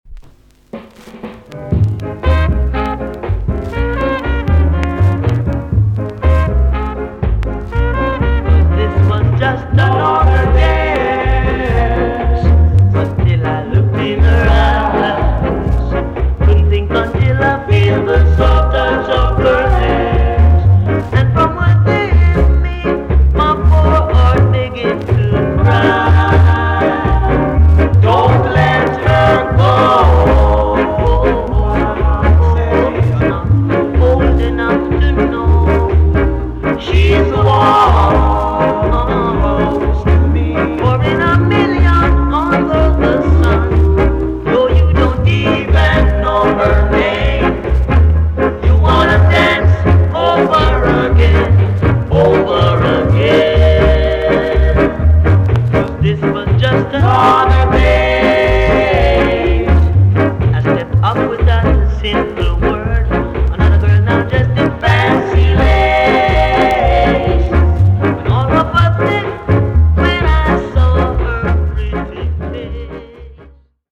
TOP >SKA & ROCKSTEADY
EX-~VG+ 少し軽いチリノイズがありますが音は良好です。